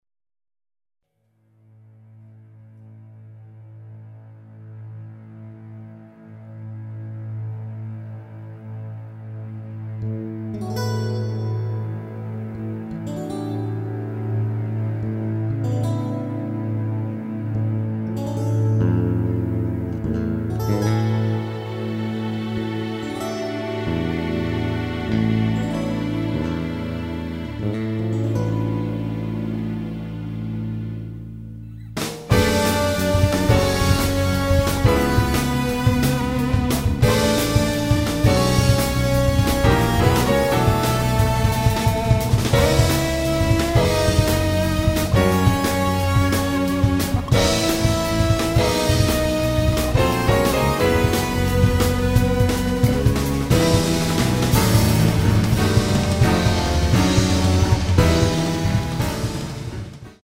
electric guitars & MIDI programming
drums
electric bass
piano
tenor saxophone